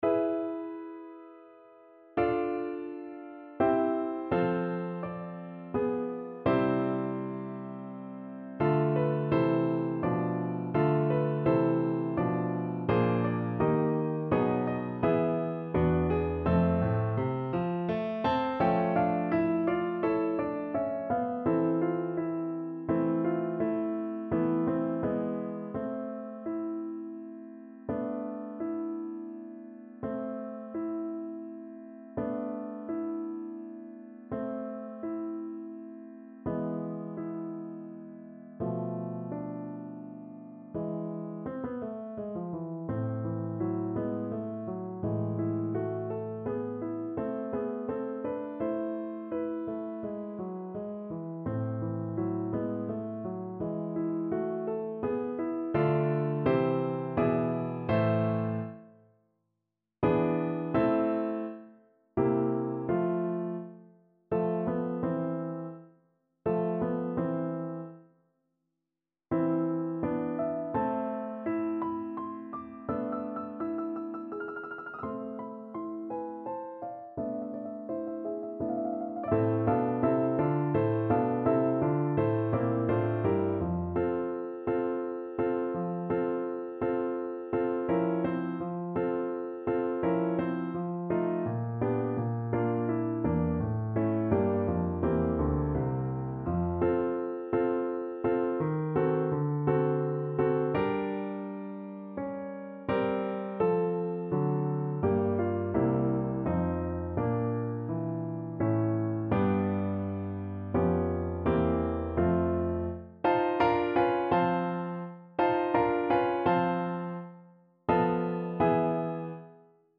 Play (or use space bar on your keyboard) Pause Music Playalong - Piano Accompaniment Playalong Band Accompaniment not yet available reset tempo print settings full screen
3/4 (View more 3/4 Music)
A minor (Sounding Pitch) (View more A minor Music for Viola )
Andante =84
Classical (View more Classical Viola Music)